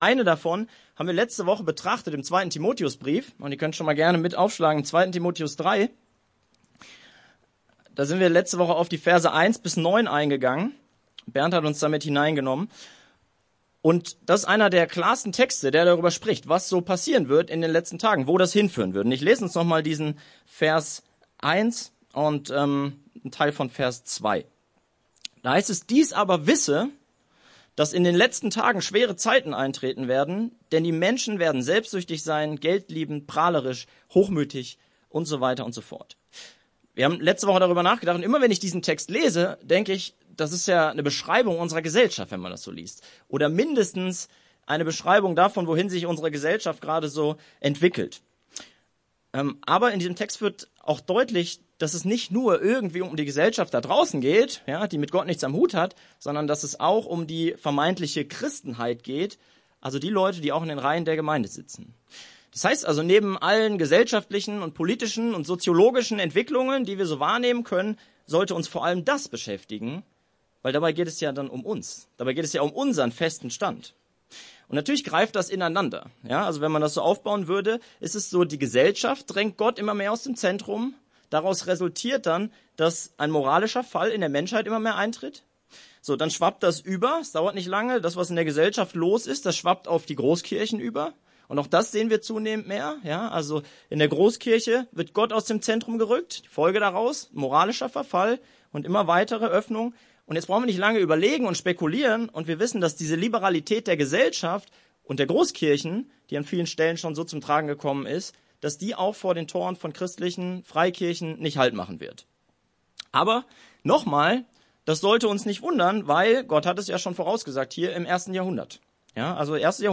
predigte über 2. Timotheus 3,10-17